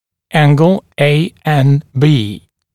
[‘æŋgl eɪ en biː][‘энгл эй эн би:]угол ANB